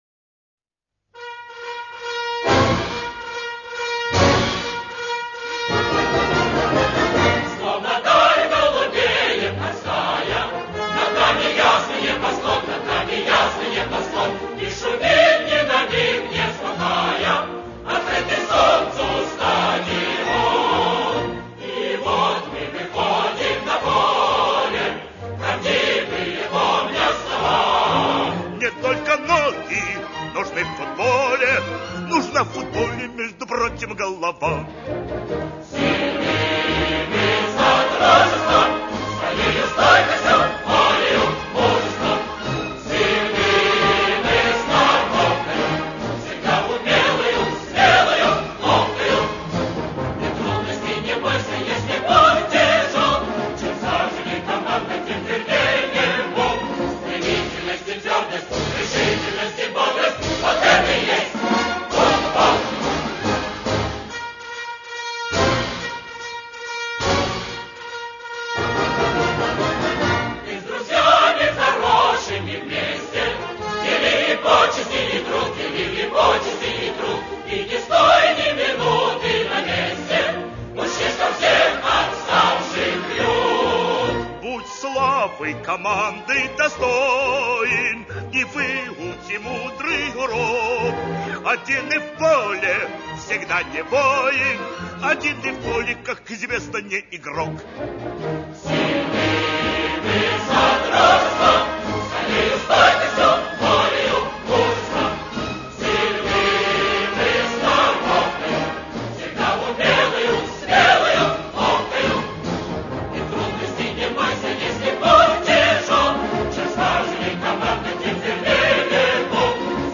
Спортивно-патриотический марш